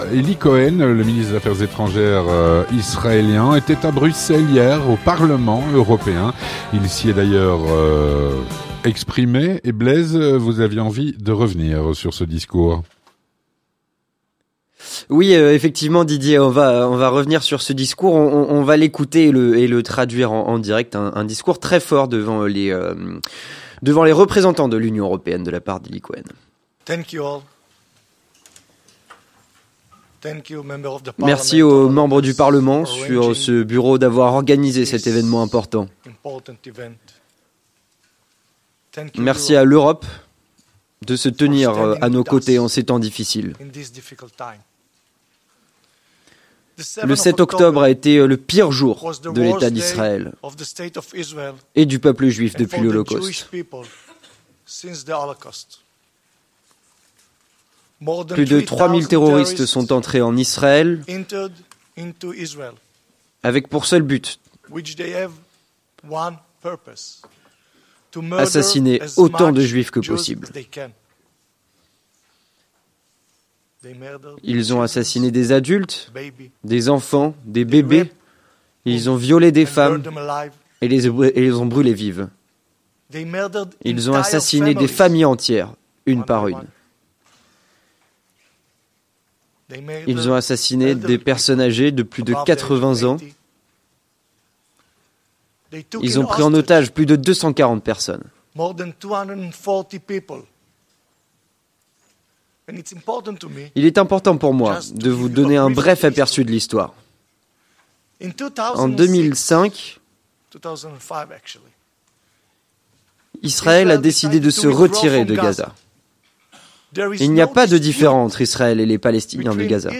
Vue de Belgique - Discours du ministre israélien des affaires étrangères au Parlement Européen. Avec Élie Cohen (09/11/2023)
Le ministre israélien des affaires étrangères était au Parlement Européen en compagnie de familles d'otages, mercredi 08 novembre, pour faire voir aux députés présents les vidéos des atrocités barbares commises par les terroristes du Hamas le 07 Octobre. À cette occasion, il a prononcé un discours fort que nous vous proposons d'écouter.
Avec Élie Cohen, Ministre israélien des affaires étrangères.